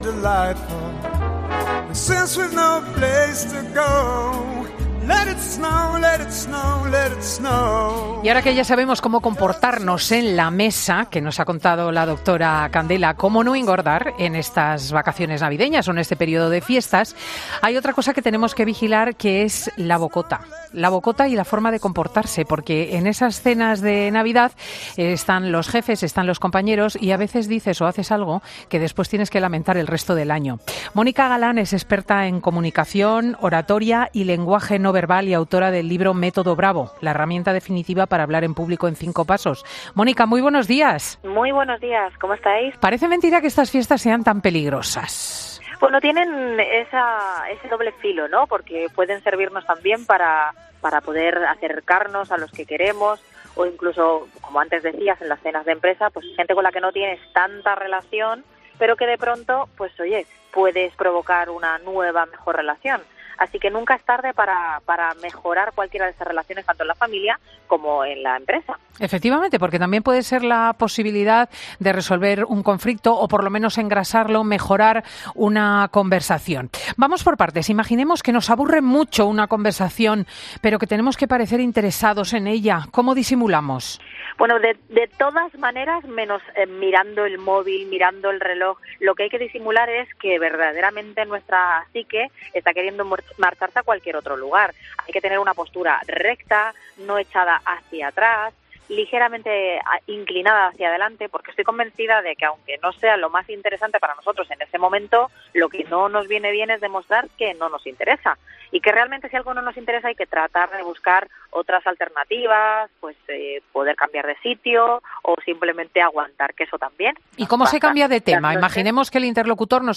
en la tertulia de chicas